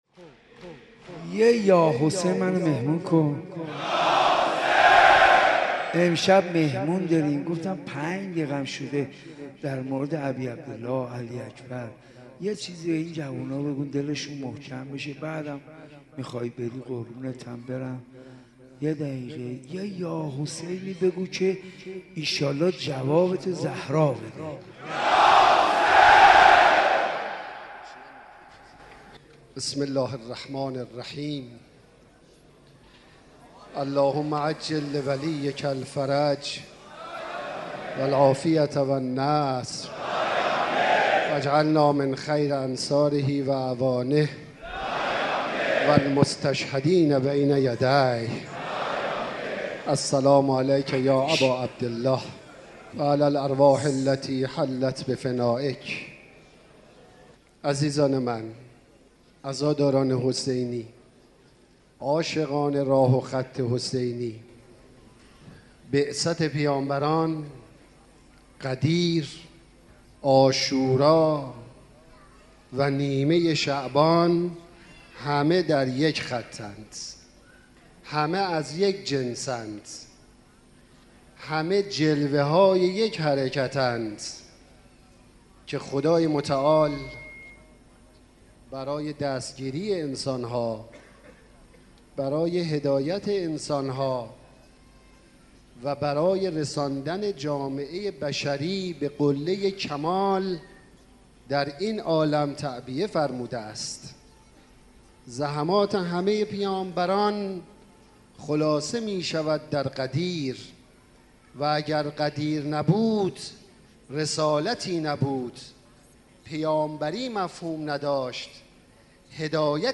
مناسبت : شب هشتم محرم
سخنرانی